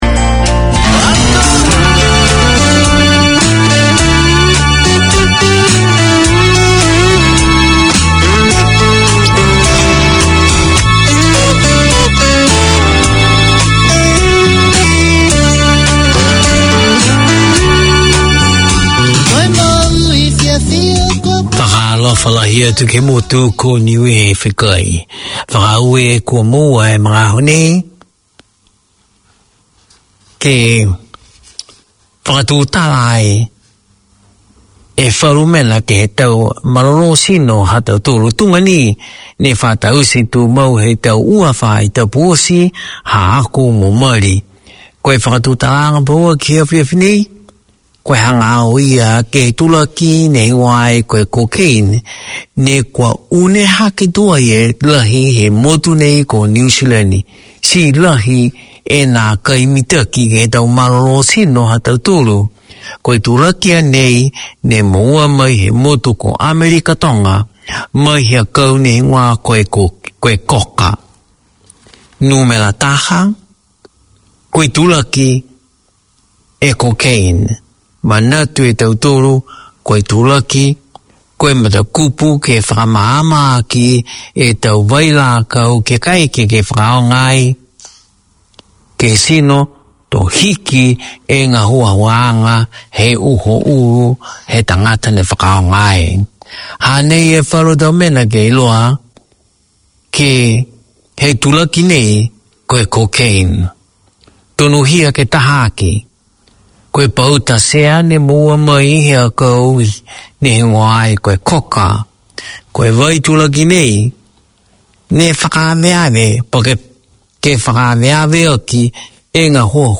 Nachda Punjab 3:15pm TUESDAY Community magazine Language